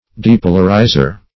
Search Result for " depolarizer" : The Collaborative International Dictionary of English v.0.48: Depolarizer \De*po"lar*i`zer\, n. (Elec.)